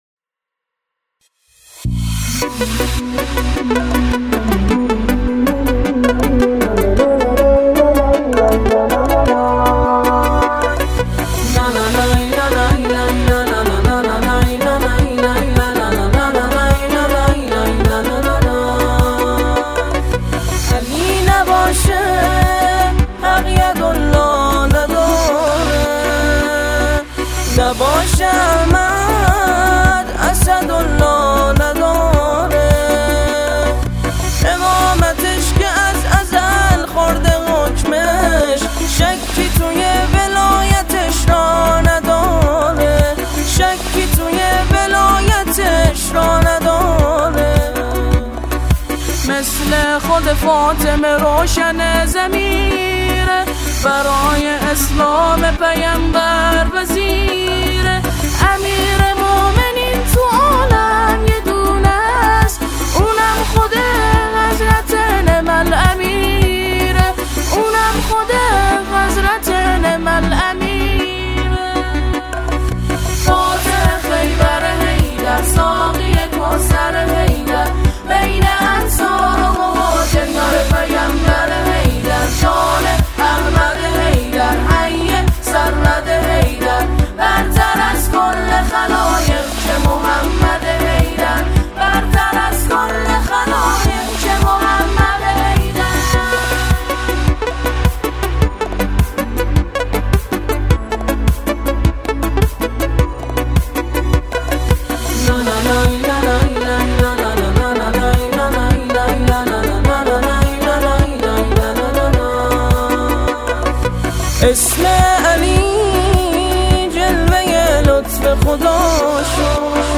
سرودهای اعیاد اسلامی